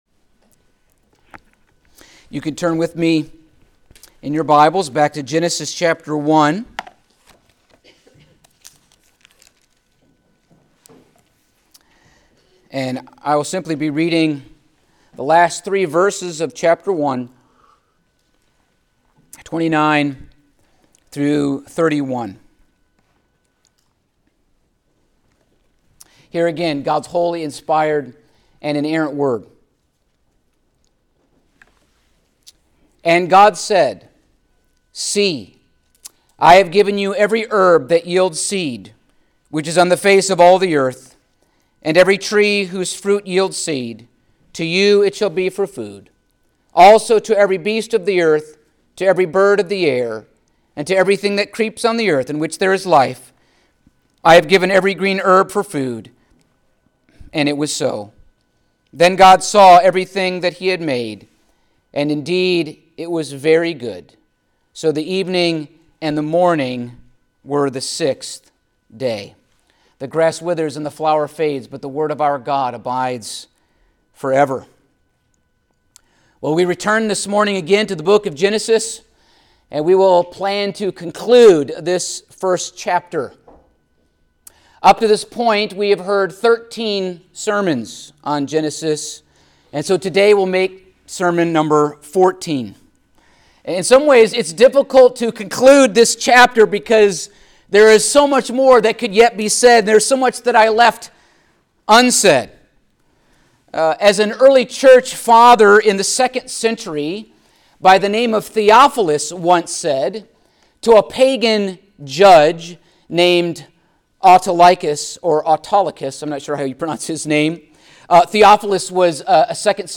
Passage: Genesis 1:29-31 Service Type: Sunday Morning